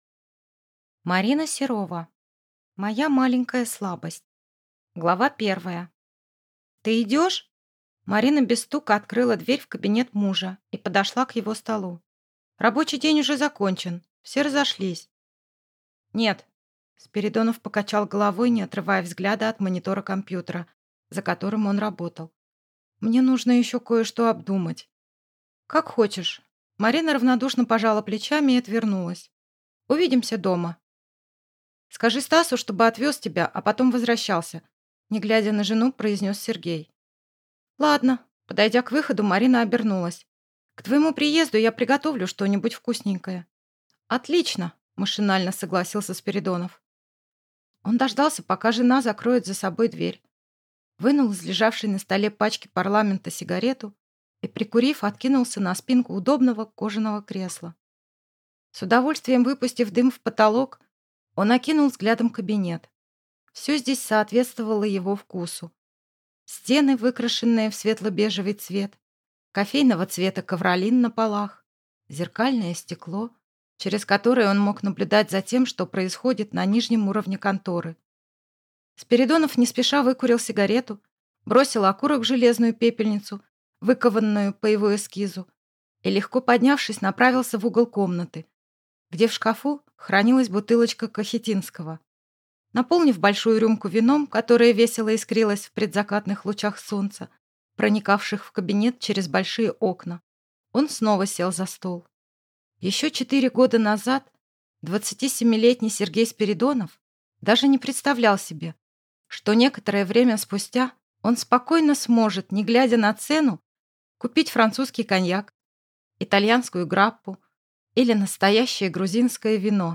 Аудиокнига Моя маленькая слабость | Библиотека аудиокниг